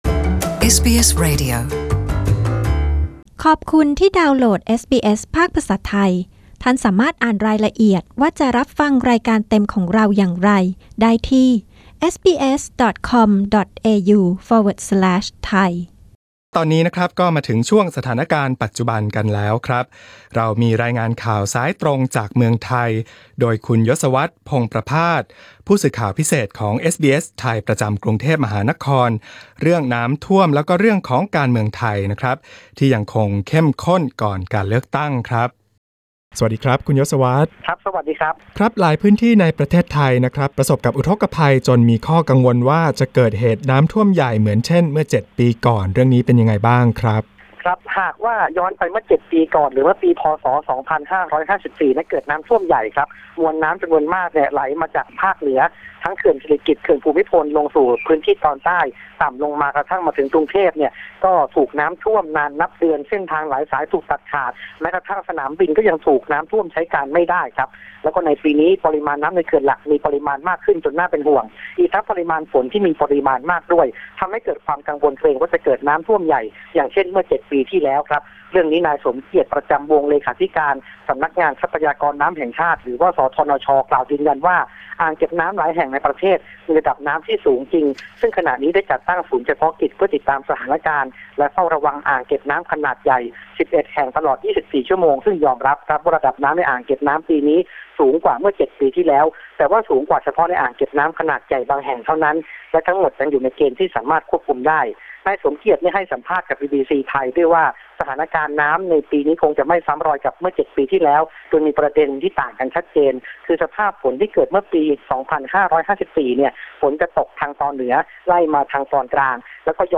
Thai phone-in news 9 AUG 2018